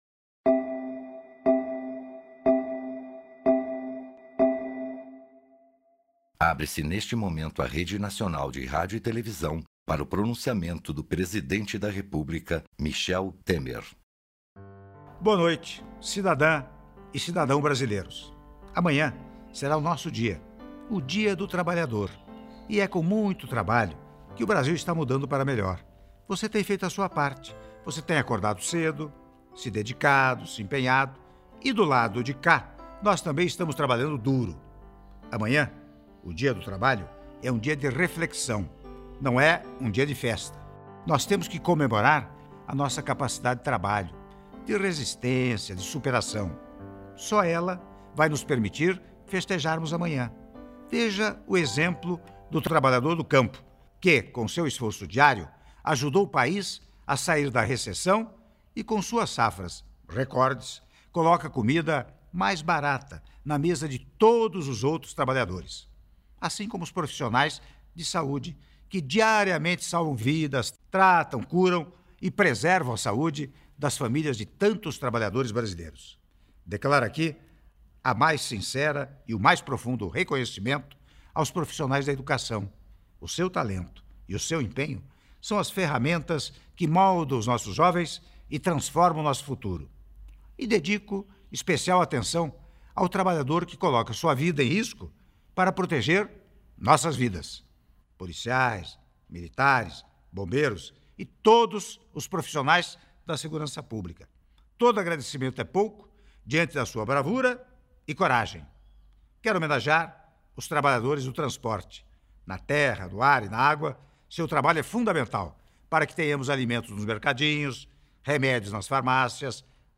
Áudio do Pronunciamento do Presidente da República, Michel Temer, em cadeia de rádio e televisão (04min30s)